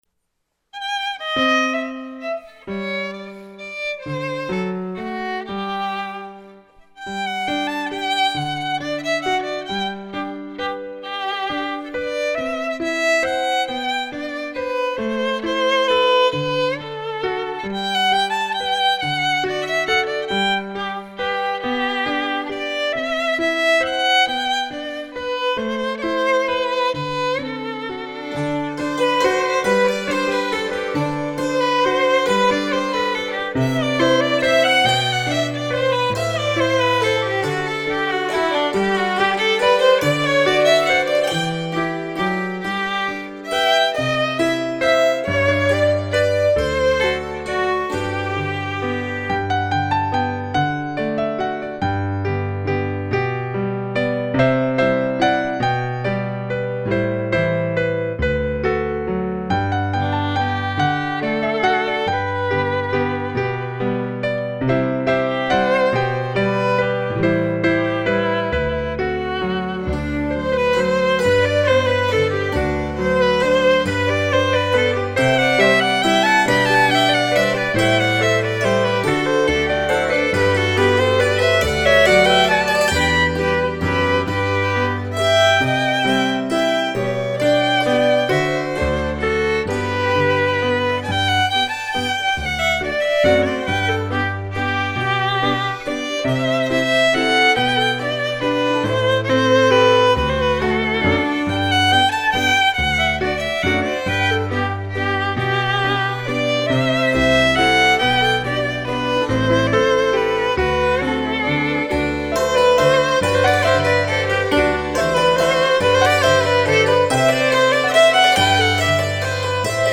Grandview Orchestra perform the "Duke of Kent's Waltz."  see Bal Masqué dancing Knole Park. and JASNA Ball, 2012 and What Jane Saw on 24 May 1813 in London